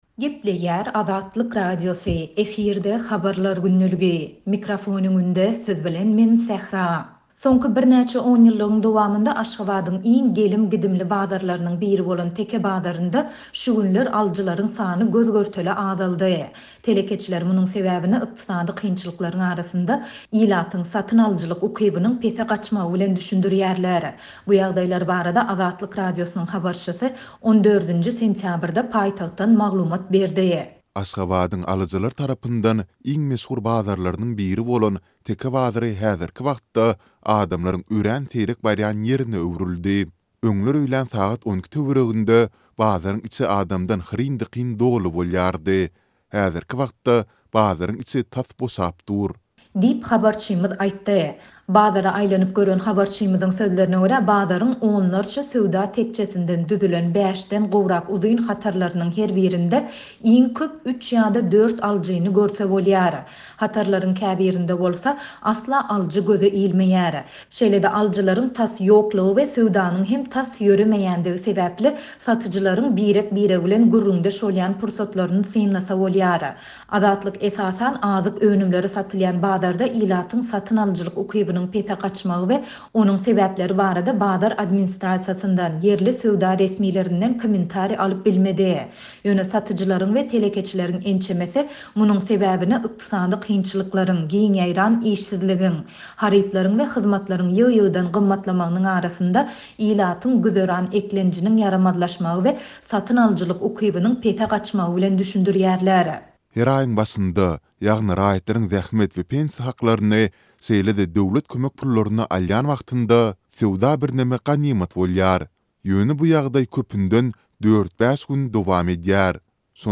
Bu ýagdaýlar barada Azatlyk Radiosynyň habarçysy 14-nji sentýabrda paýtagtdan maglumat berdi.